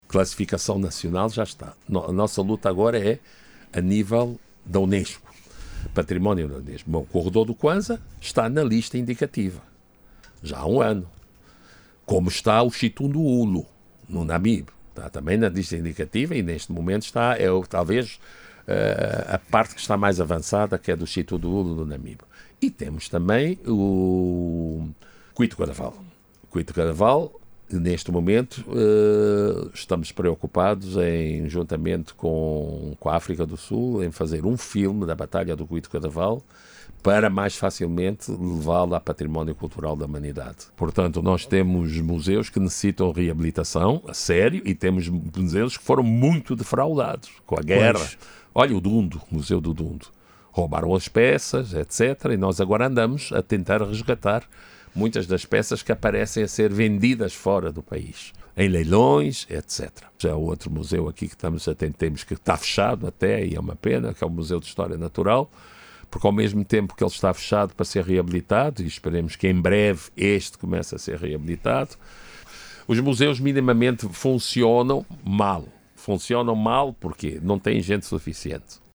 E o Ministro da Cultura, Filipe Zau, diz que esta em curso trabalhos de reabilitação dos Museus em todo país.